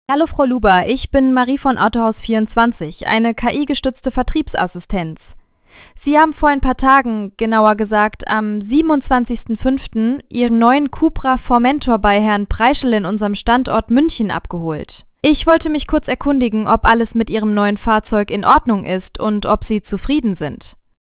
o Format: WAV, mono, 44.1 kHz (or 48 kHz), PCM Linear (pcm_s16le), typical web-optimized
• Source files are generated via ElevenLabs (MP3), then converted with ffmpeg, e.g.: